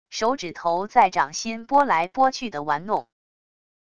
手指头在掌心拨来拨去的玩弄wav音频